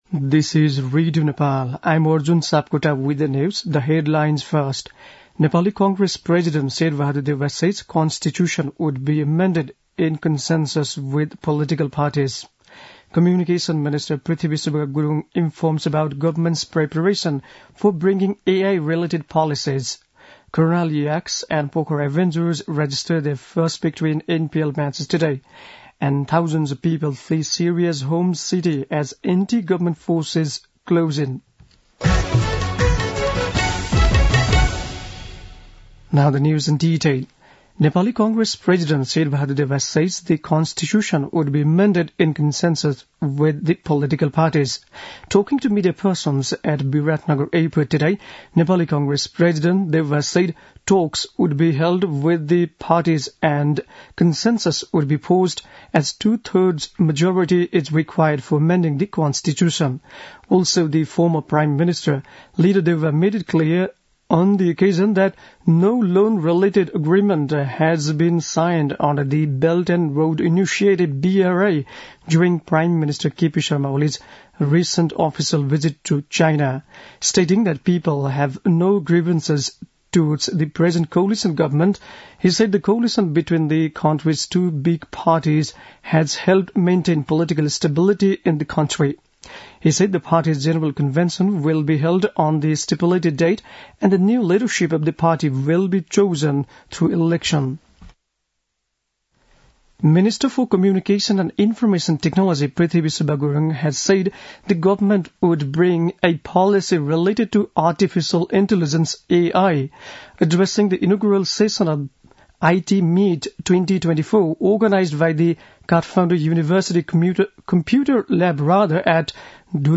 बेलुकी ८ बजेको अङ्ग्रेजी समाचार : २२ मंसिर , २०८१